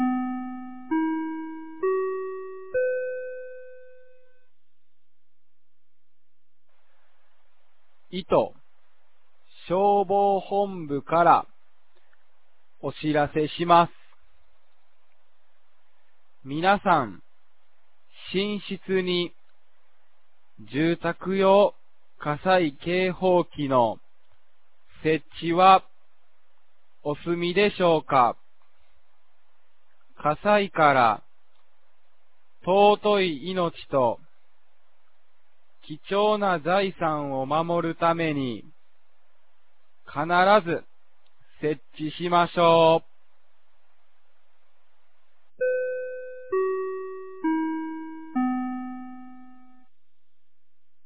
2025年04月28日 10時01分に、九度山町より全地区へ放送がありました。